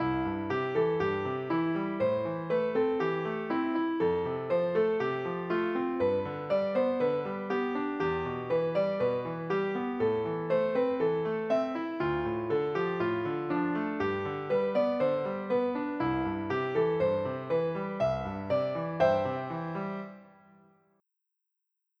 Compose a 4-bar piano melody in C major at 120 BPM
Result: 4-bar C major melody at 120 BPM composed with Gemini 3.1 Pro
midieditor_ai_piano_piece.wav